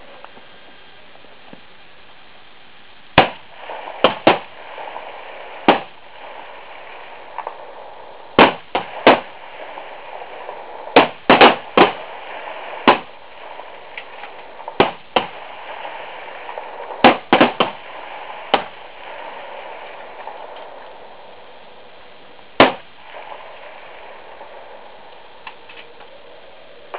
Ta hem en del av skjutbanan till din dator!
skott.WAV